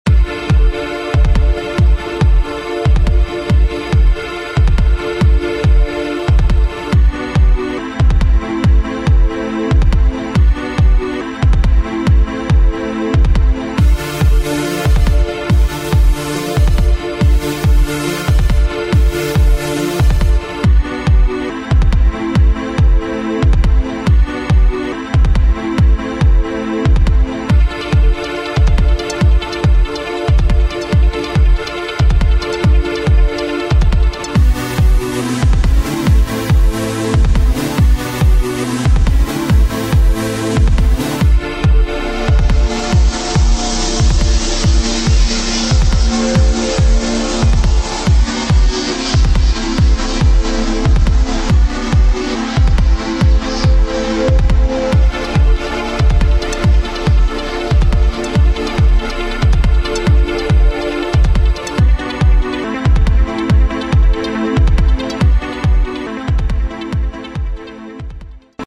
This track is just a bunch of fruity loops synth presets dropped in a simple arrangement.
Filed under: Instrumental | Comments (1)